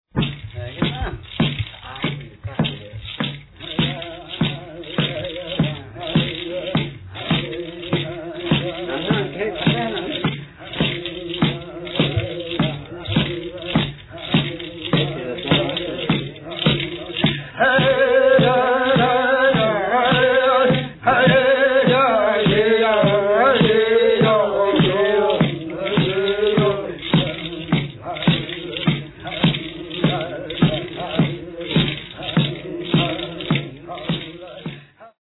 Burial Song - 1:23